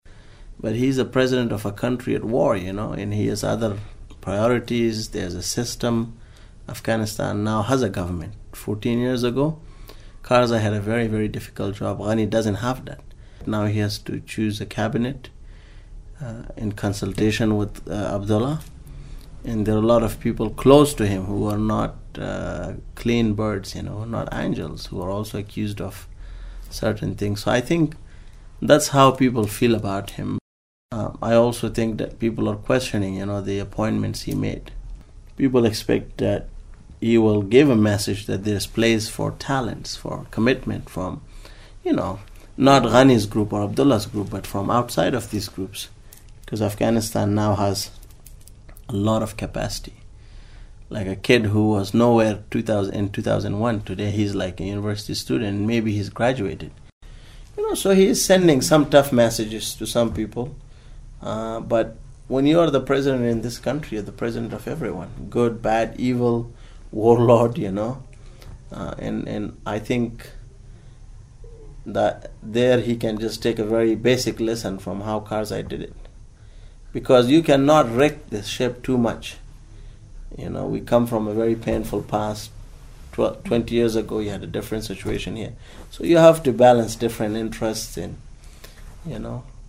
Afghanistan is in a much better situation than it was 14 years ago, a Kabul journalist says, but it is still a country at war: